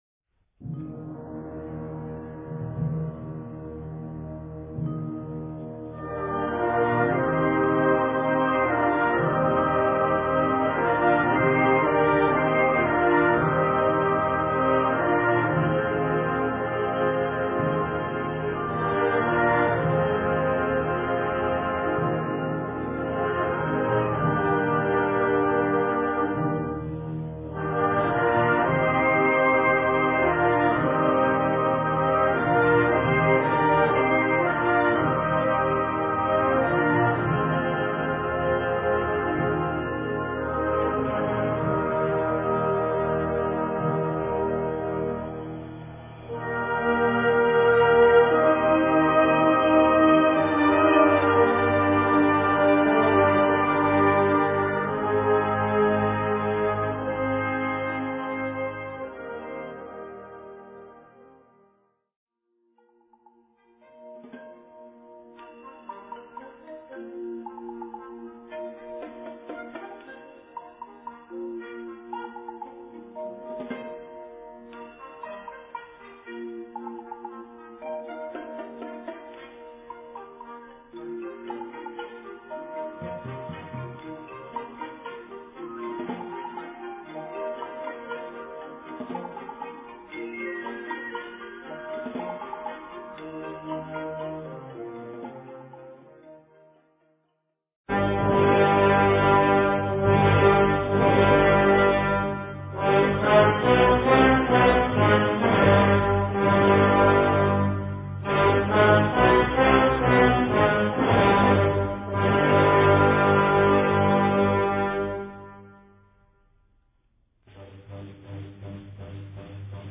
Categorie Harmonie/Fanfare/Brass-orkest
Subcategorie Hedendaagse blaasmuziek (1945-heden)
Bezetting Ha (harmonieorkest)
Het betoveringsthema van het begin klinkt in totale glorie.